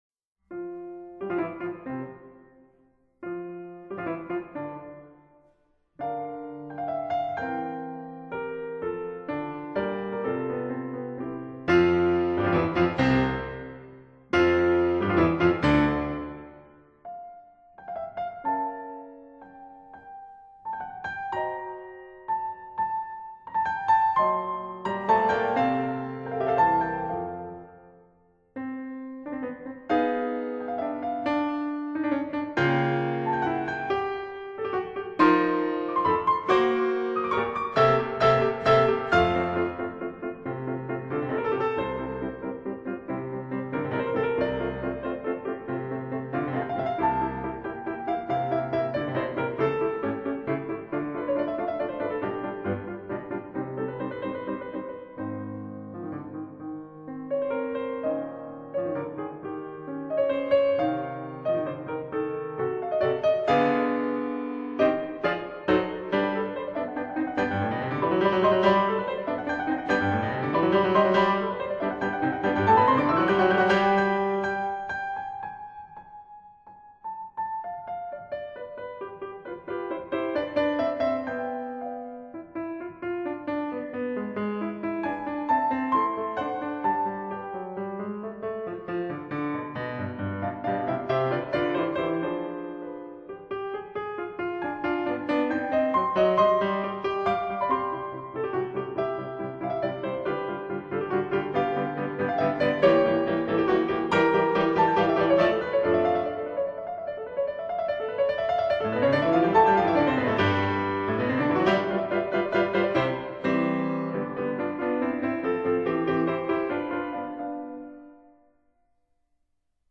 這是貝多芬 - 弦樂四重奏改成為鋼琴四手聯彈
transcribed for piano four hands